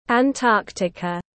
Antarctica /ænˈtɑːk.tɪ.kə/